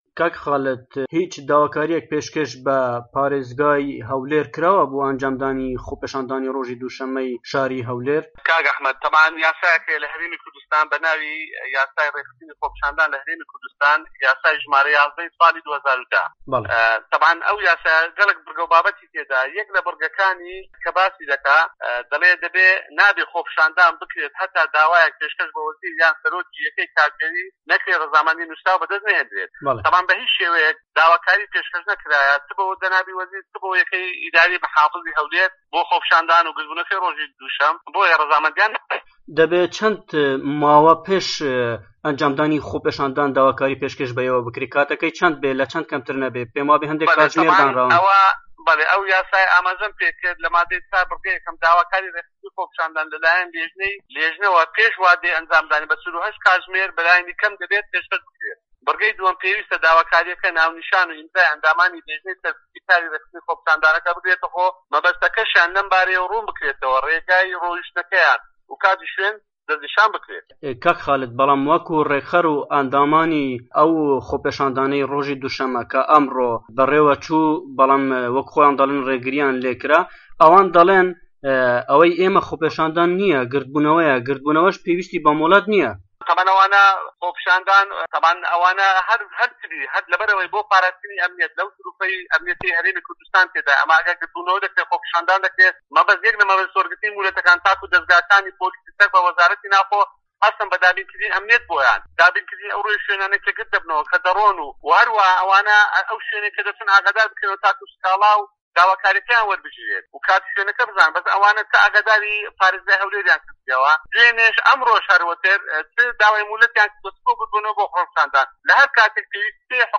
وتووێژ لەگەڵ عەمیدی مافپەروەر خالید عەباس